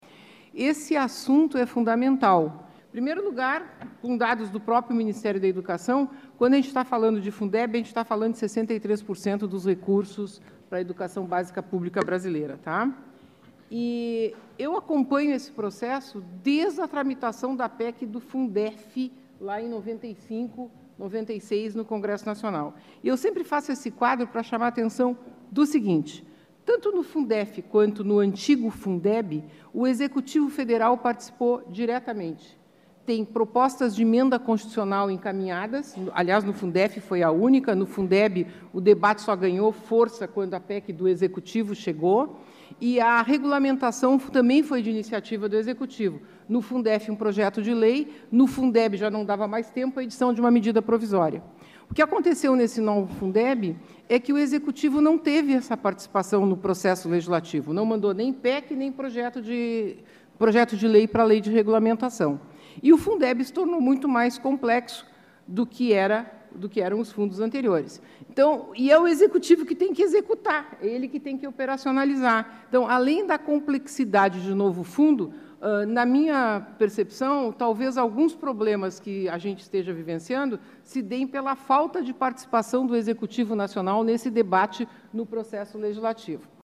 IV Sined e III Encontro de Promotores e Promotoras de Justiça da Educação - áudios dos participantes
Painel "Principais aspectos controvertidos do Fundeb"